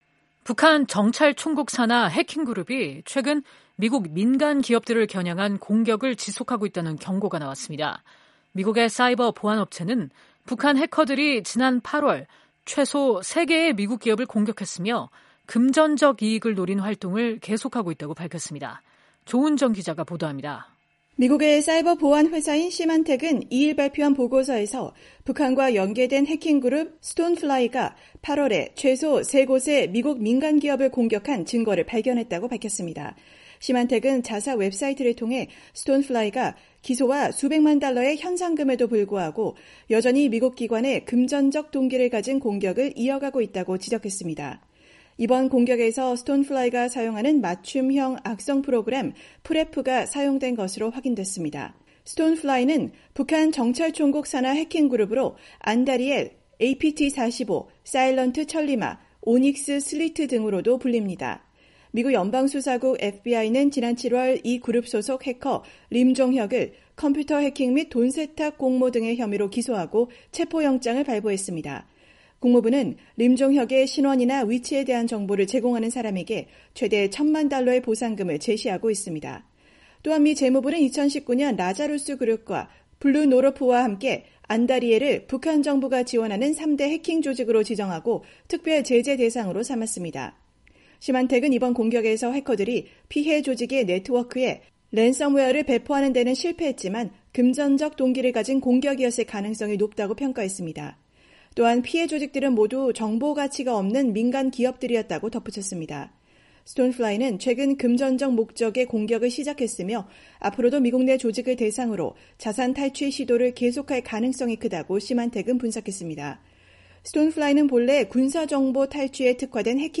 북한 정찰총국 산하 해킹그룹이 최근 미국 민간기업들을 겨냥한 공격을 지속하고 있다는 경고가 나왔습니다. 미국의 사이버 보안업체는 북한 해커들이 지난 8월 최소 3개의 미국 기업을 공격했으며, 금전적 이익을 노린 활동을 계속하고 있다고 밝혔습니다.